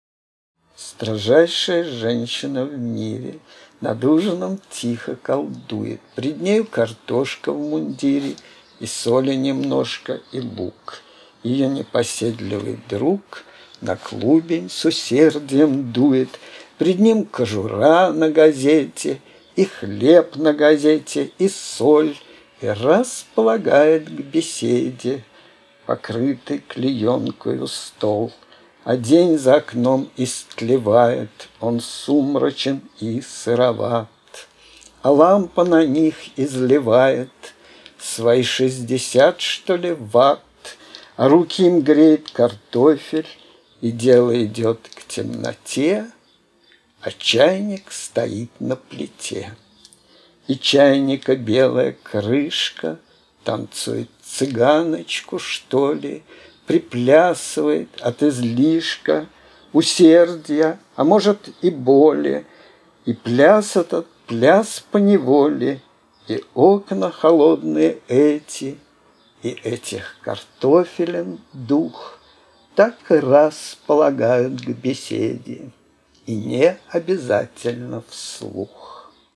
скачать – авторское исполнение
uzhin-golos.mp3